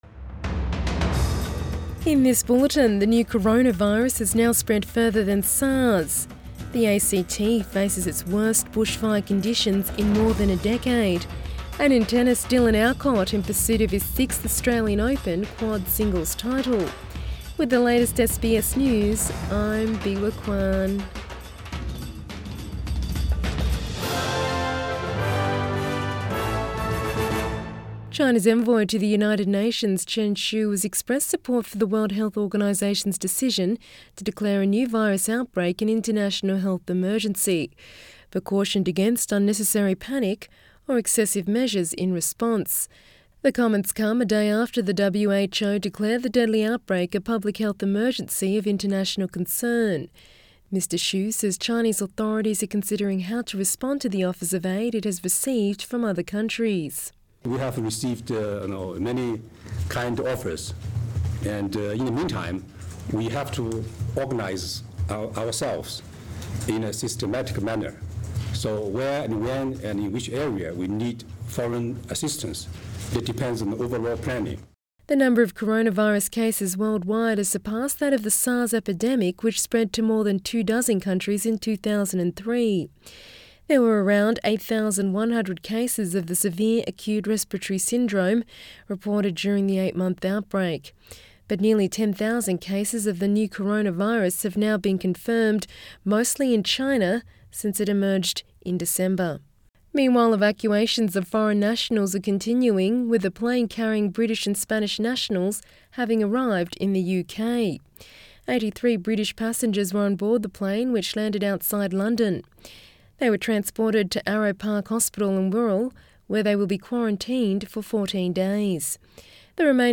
AM bulletin 1 February 2020